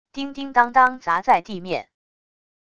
叮叮当当砸在地面wav音频